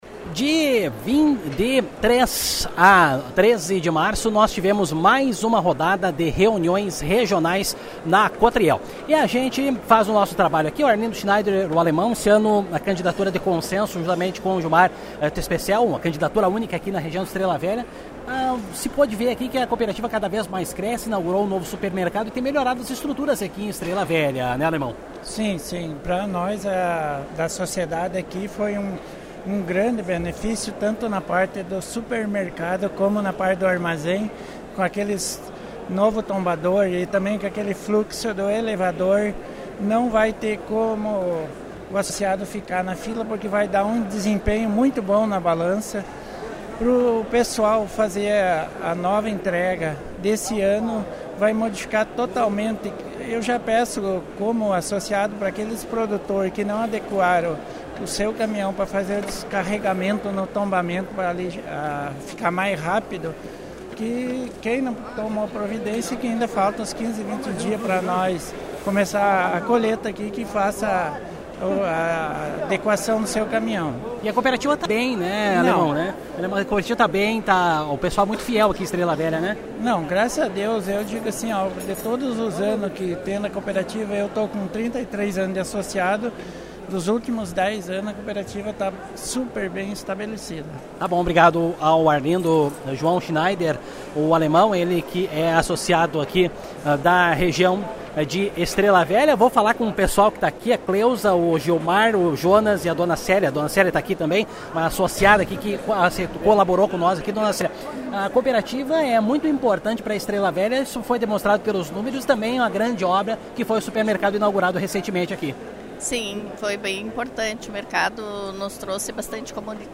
A primeira  reunião regional foi realizada na manhã de 03 de março, no Ginásio Estrelão, de Estrela Velha.
conversa com alguns associados presentes